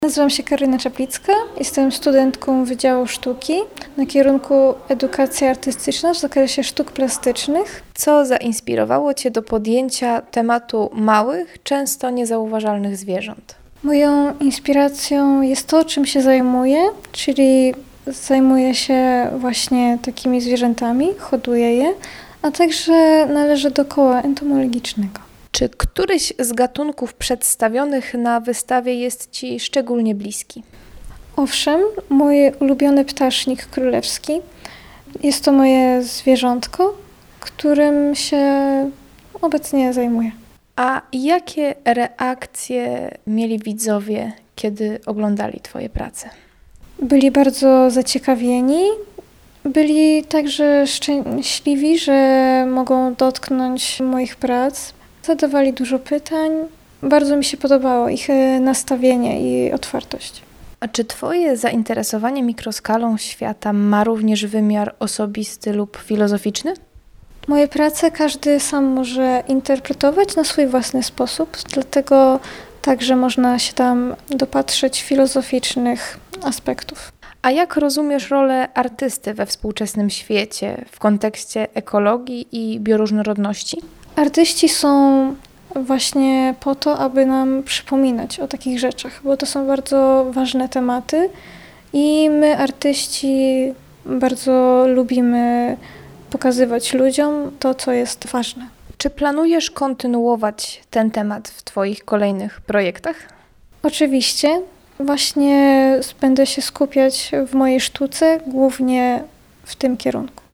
Wernisaż odbył się we wtorek 6 maja.